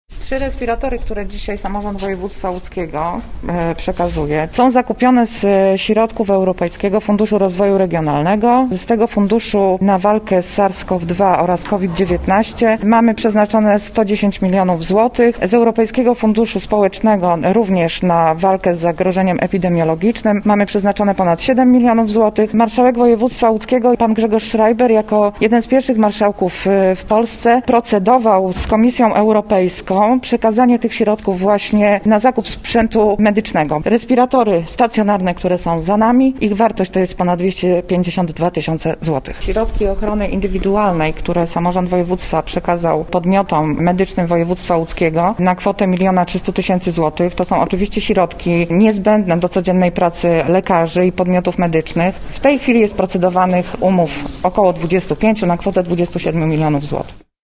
– mówiła Iwona Koperska, przewodnicząca Sejmiku Województwa Łódzkiego.